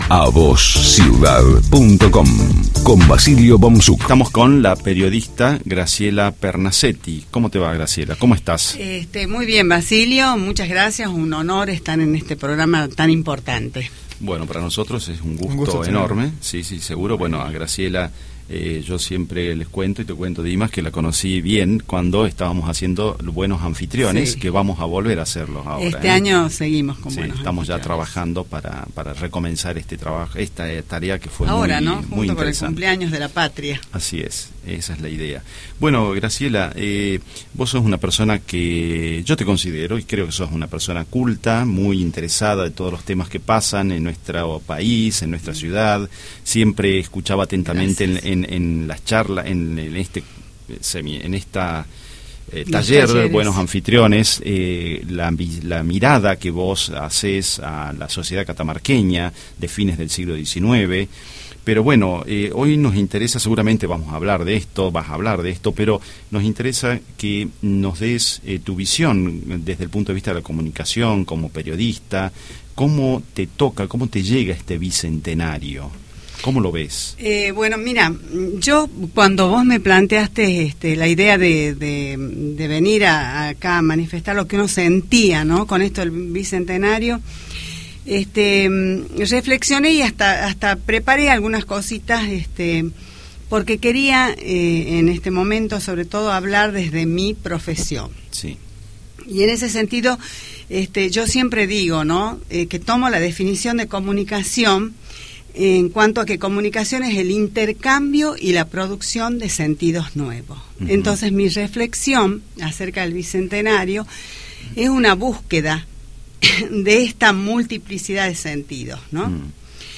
AUDIO DE LA ENTREVISTA Cómo celebrar el Bicentenario de la Patria. Qué debemos rescatar de esta fecha tan importante para los argentinos.